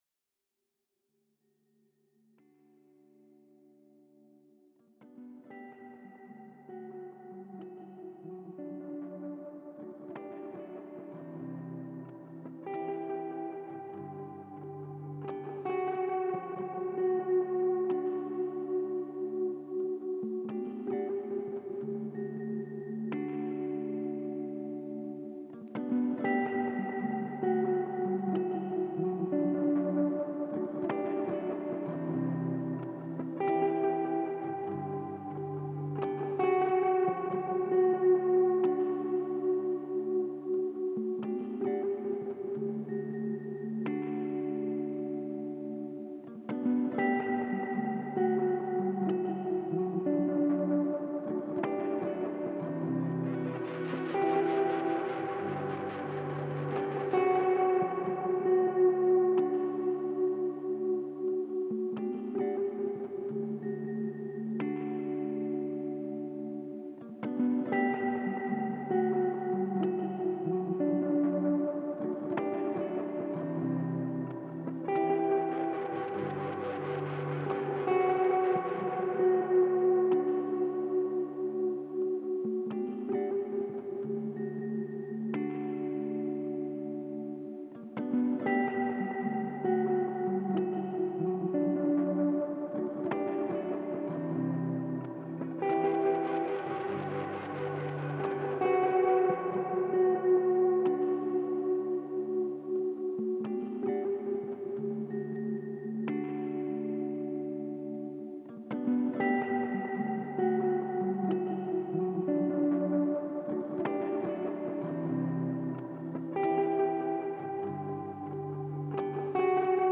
Ambient Music and Thought Sounds
These days, I have an effects rig to distort, delay, and loop my single coil signals as I strive to get further away from discernable music. The recordings are usually live and one-take. lost world Sample 2 Sample 3 Sample 4